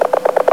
HEART.WAV